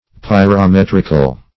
Meaning of pyrometrical. pyrometrical synonyms, pronunciation, spelling and more from Free Dictionary.